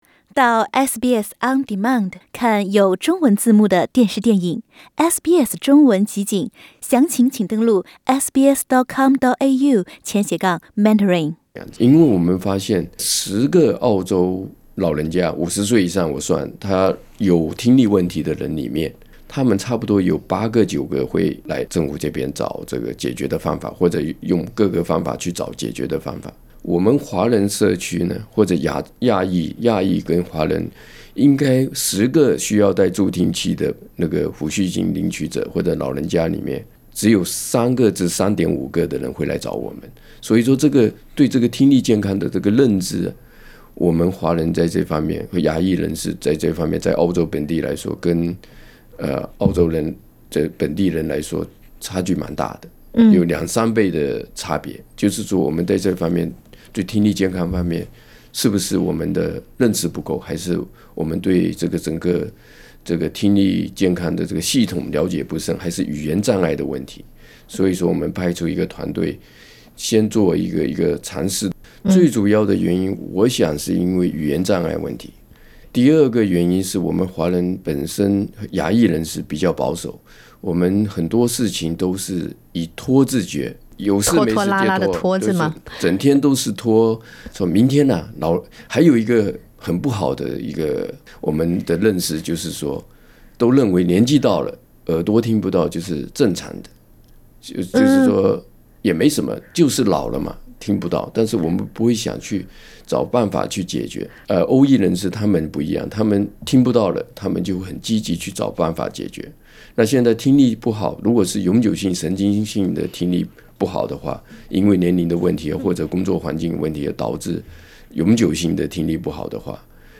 点击上方图片收听采访录音。